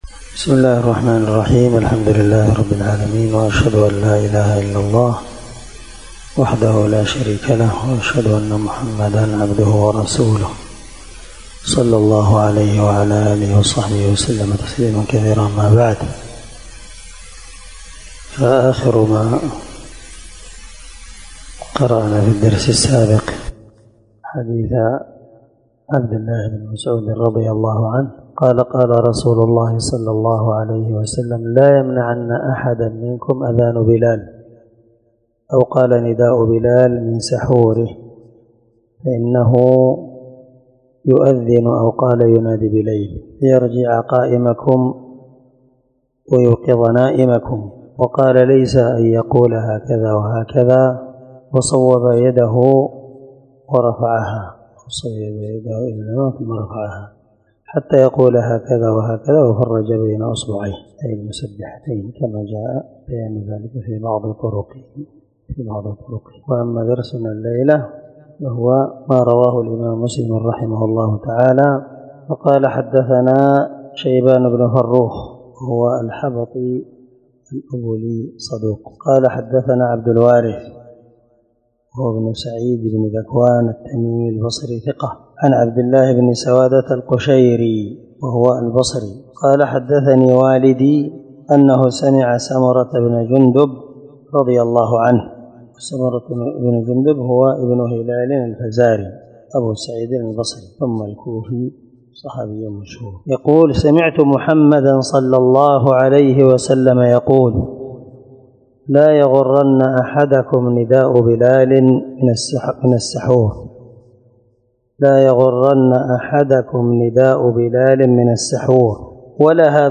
سلسلة_الدروس_العلمية
دار الحديث- المَحاوِلة- الصبيحة.